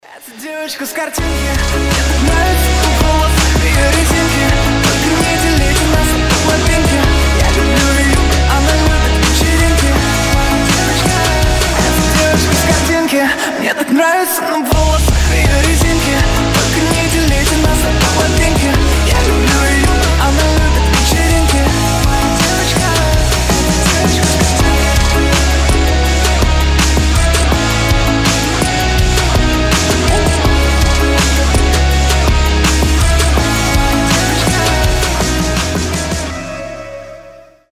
• Качество: 320, Stereo
громкие
Pop Rock
Рок-версия популярного рэп-трека